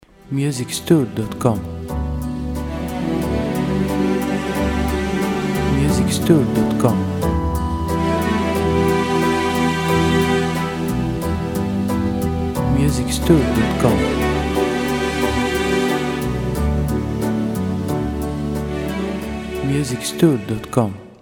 • Type : Instrumental / Audio Track
• Bpm : Moderato
• Genre : Action / Battle Soundtrack , Ambient & Cinematic.